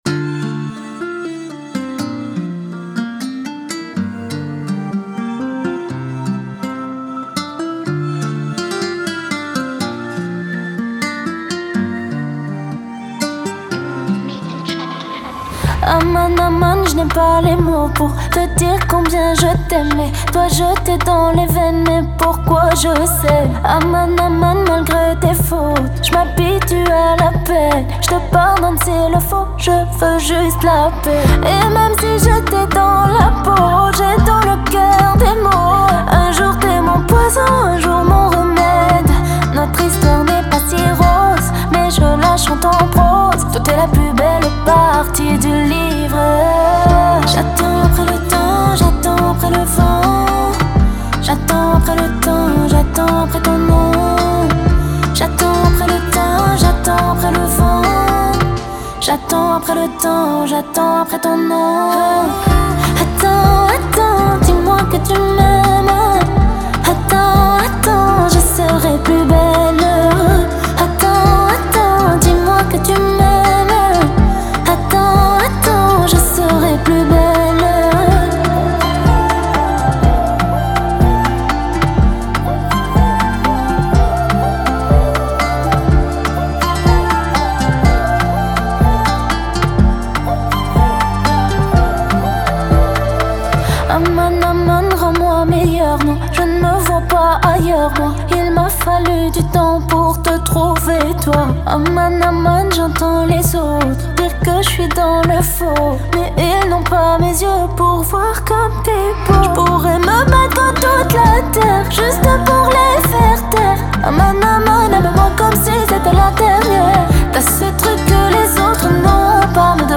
raï, moroccan chaabi Écouter sur Spotify